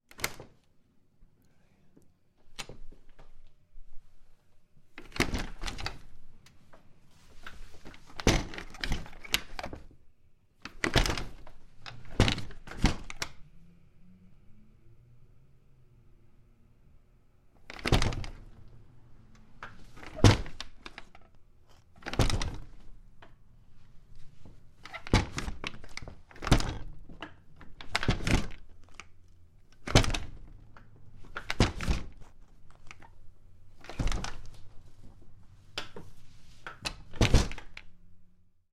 描述：门木粗糙打开关闭，松散旋钮和镶嵌玻璃close.flac
Tag: 打开 关闭 木材 玻璃 松散 镶嵌 粗糙的 旋钮